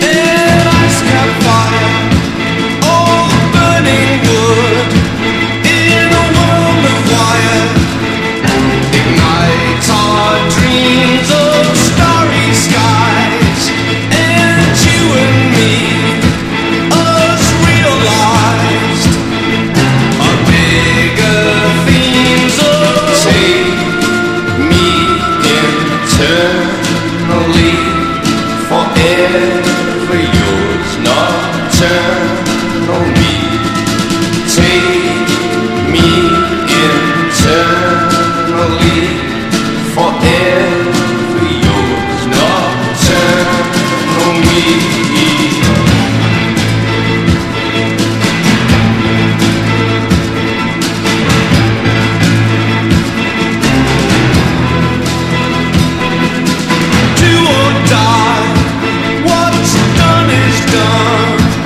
NEW WAVE / NEO PSYCHE